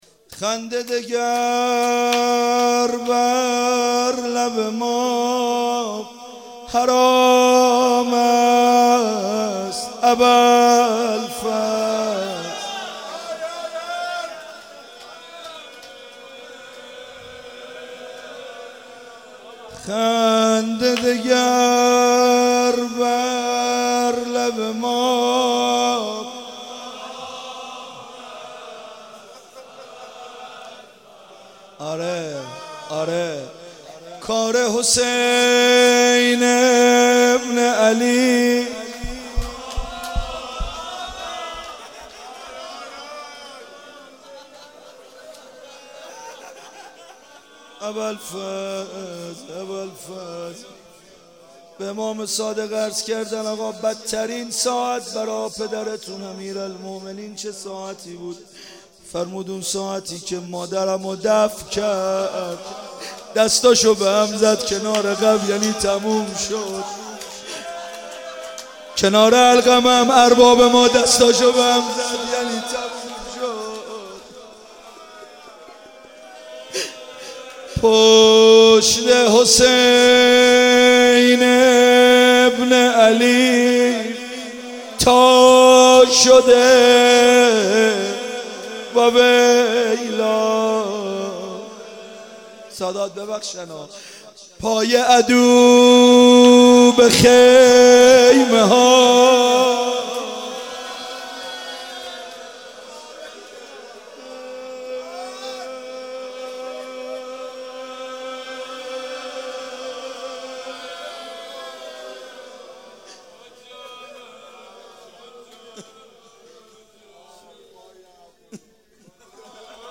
روضه پایانی